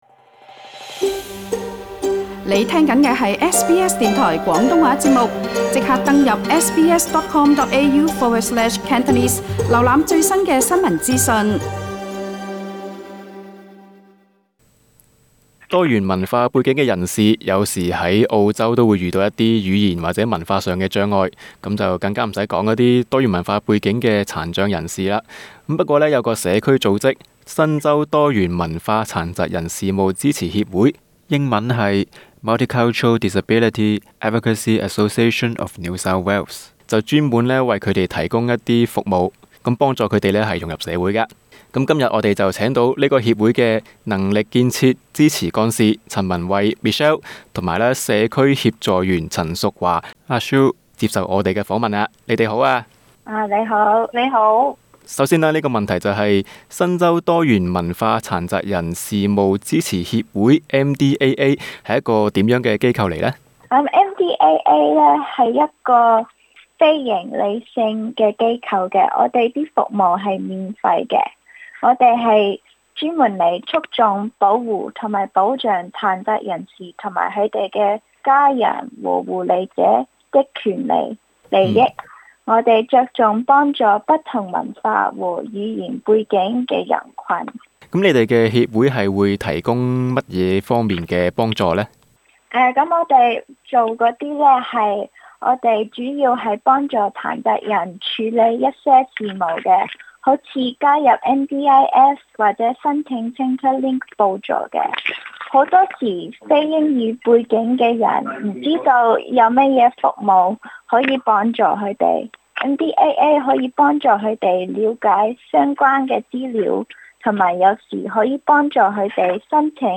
【社區訪問】